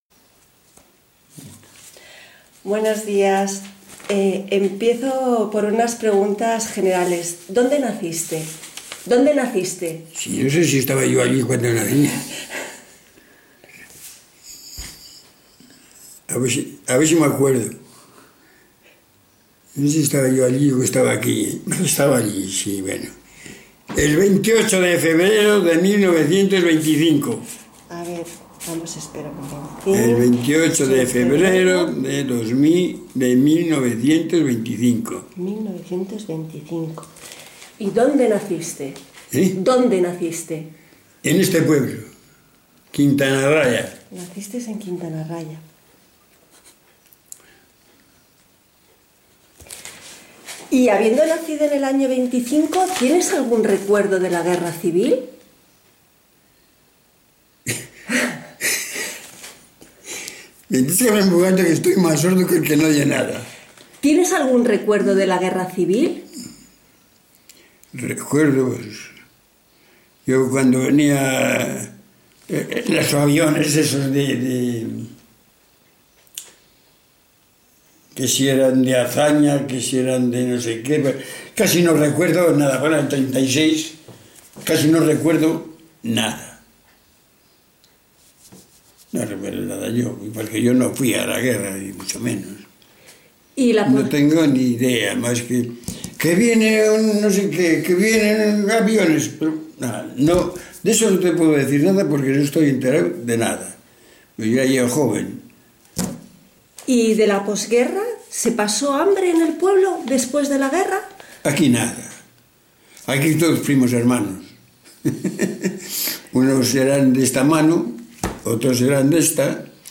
Enclave Quintanarraya (Huerta de Rey)